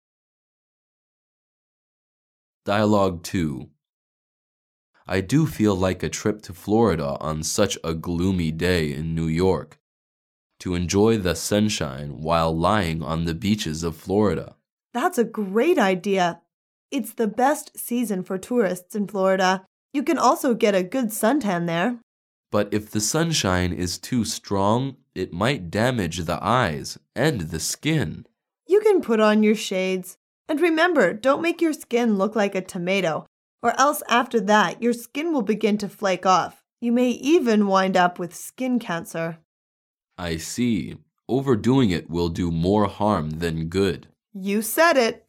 英语时尚口语情景对话Unit 6：日光浴6-2 mp3
Dialouge 2